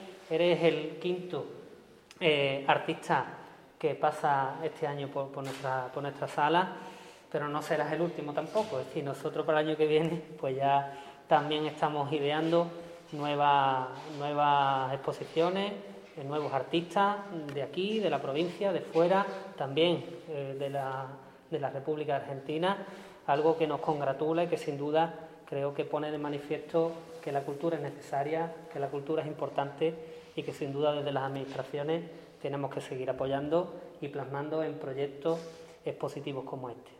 Corte de Antonio González Mellado